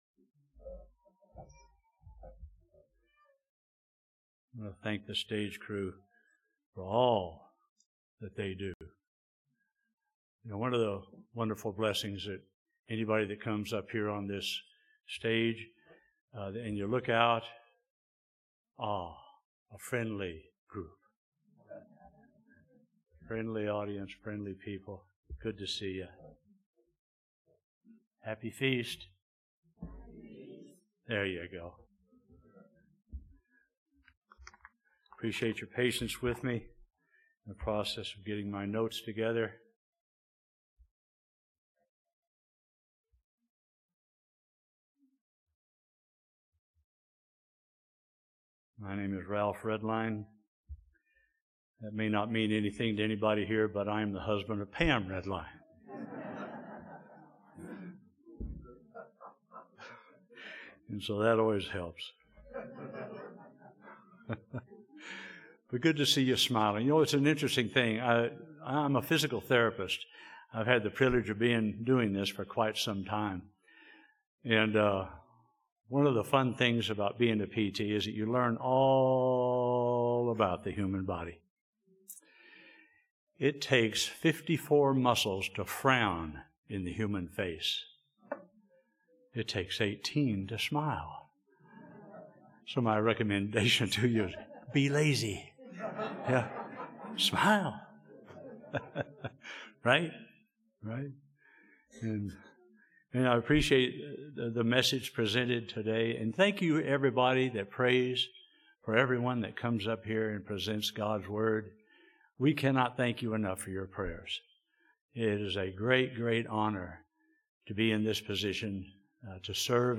Split Sermon 2 - Day 7 - Feast of Tabernacles - Klamath Falls, Oregon
This sermon was given at the Klamath Falls, Oregon 2024 Feast site.